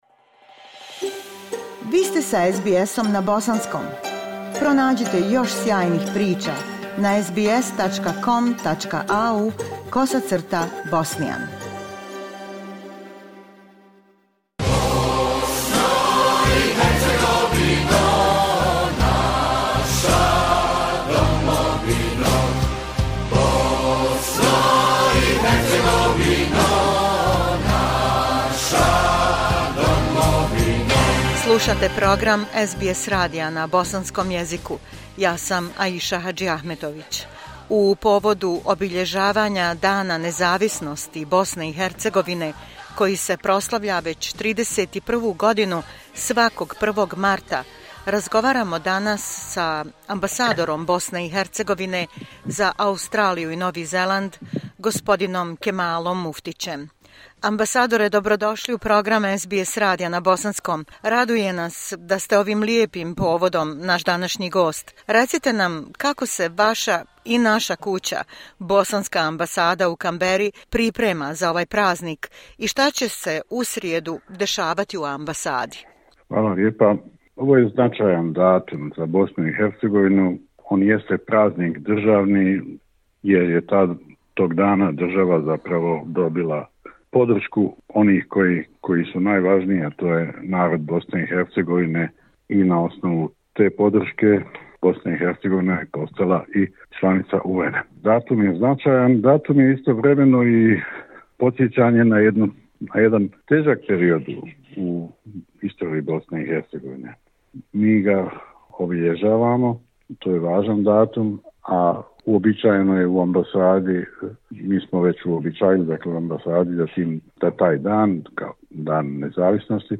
On the occasion of the Independence Day of BiH, which Bosnians and Herzegovinians celebrate in their homeland and around the world every March 1, we spoke with the Ambassador of BiH for Australia and New Zealand, Mr Kemal Muftić. New initiatives, relations with communities... are some of the topics.